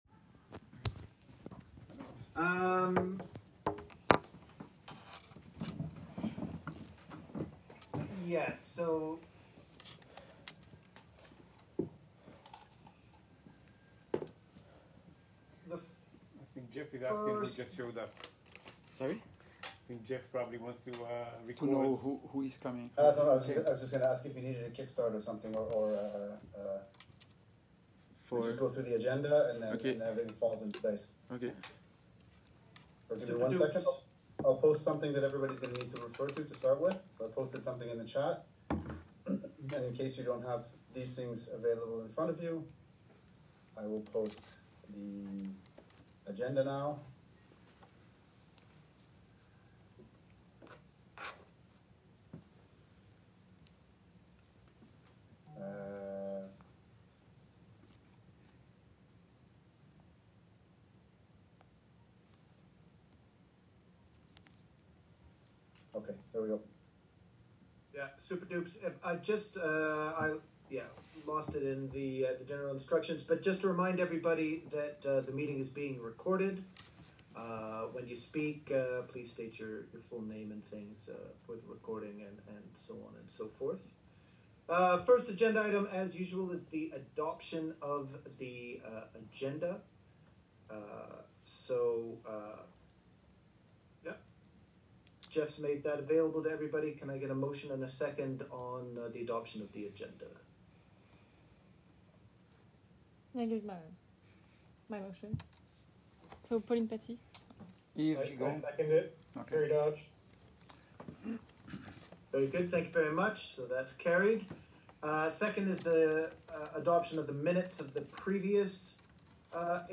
2024 AGM — Annual General Meeting / AGA 2024 — Assemblée générale annuelle